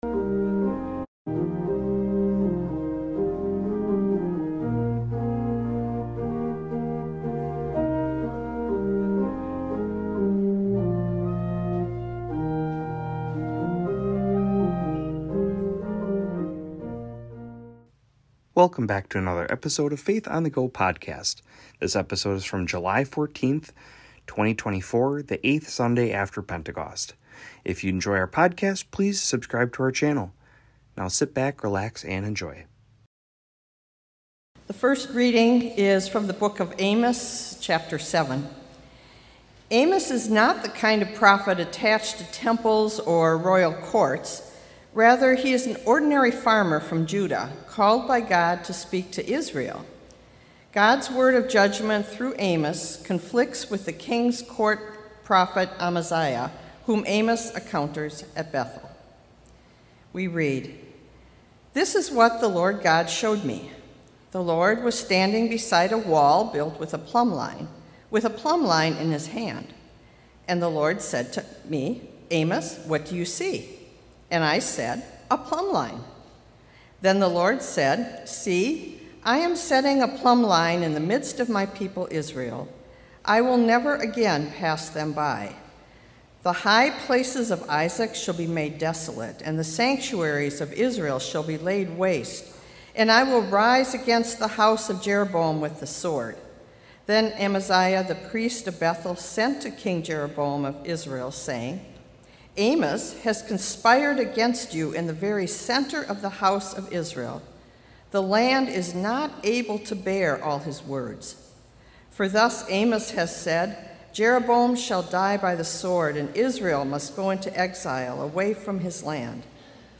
This week is from the eighth Sunday after pentecost, the service date of 7/14/24.